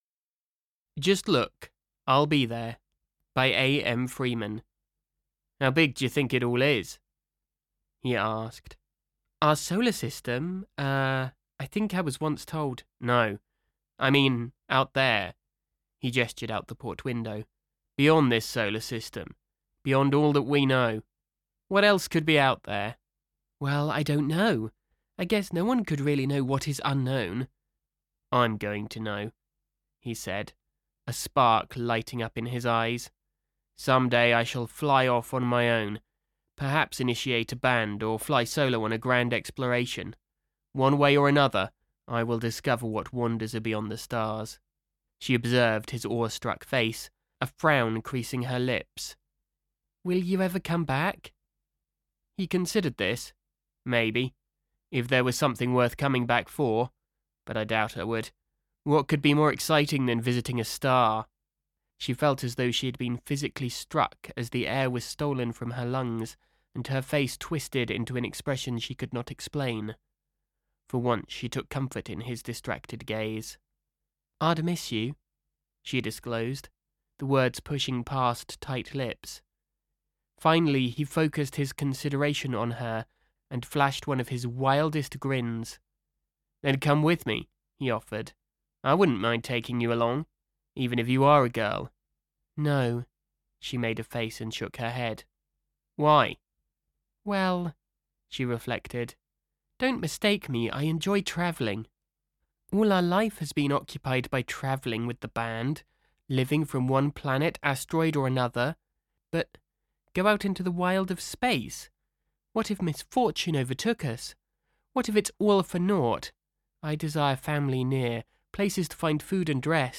Or of course, if you want to quickly find some voice samples, look no further than the below.